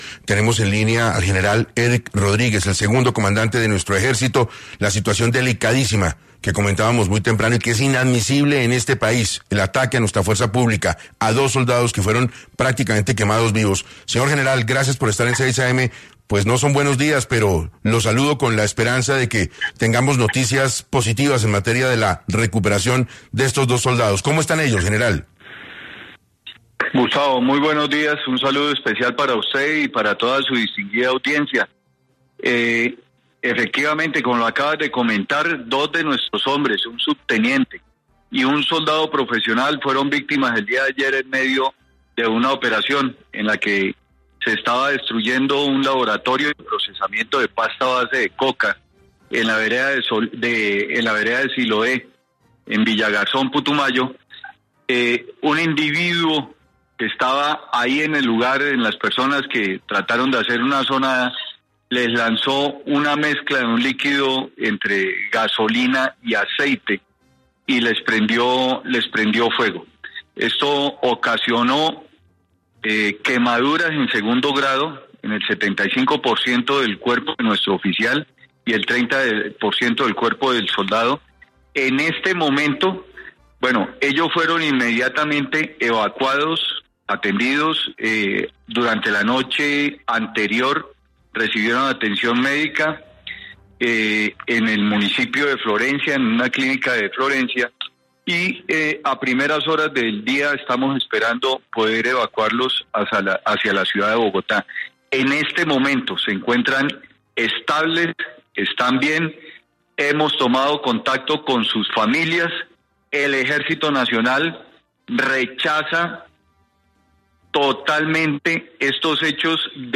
En entrevista con 6AM, Erik Rodríguez, comandante aseguró: “Tenemos imágenes del perpetrador, estamos en proceso de lograr su identificación (…) Esto no va a quedar impune, vamos a llevarlo hasta las últimas consecuencias” afirmó.